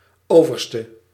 Ääntäminen
Ääntäminen RP : IPA : /ˈæb.ət/ GenAm: IPA : /ˈæb.ət/ US : IPA : [ˈæ.bət] Lyhenteet ja supistumat Ab abb.